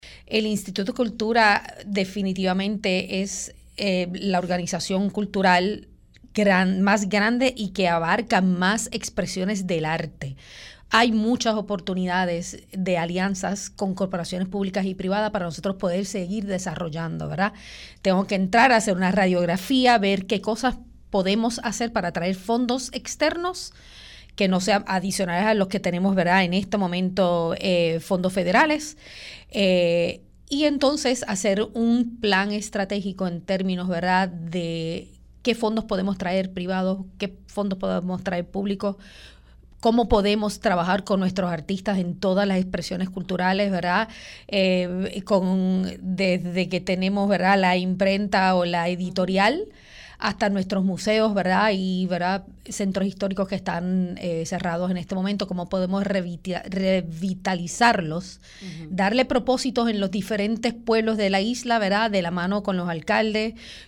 En su primera entrevista como directora ejecutiva, Melissa Santana indicó que su prioridad es analizar el presupuesto asignado de la agencia